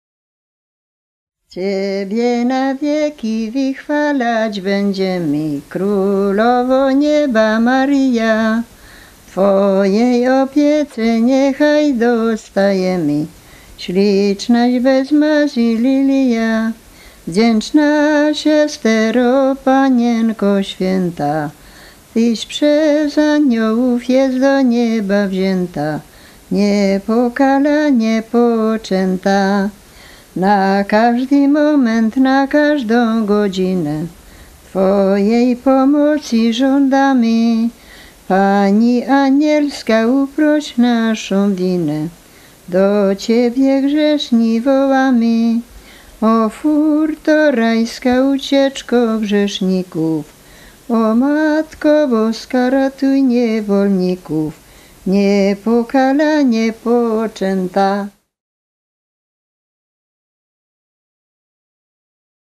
Kurpie
województwo mazowieckie, powiat wyszkowski, gmina, Długosiodło, wieś Długosiodło
maryjne nabożne katolickie